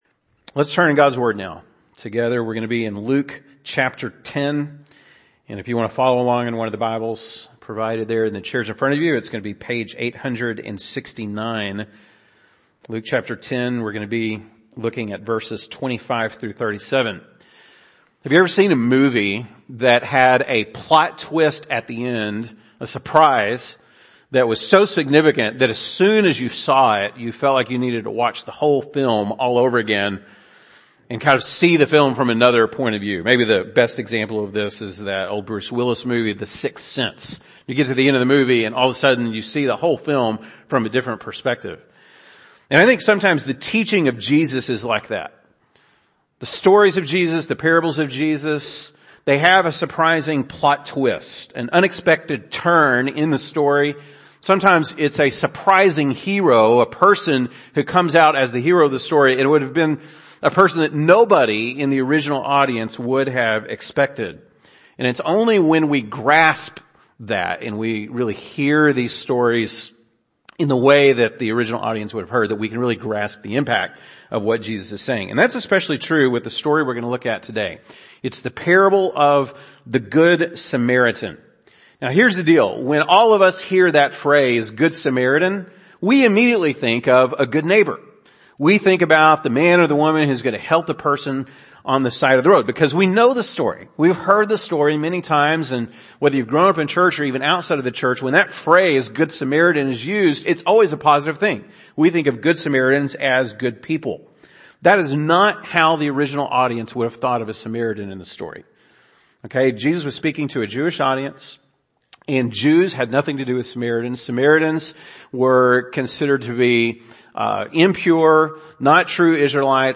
February 20, 2022 (Sunday Morning)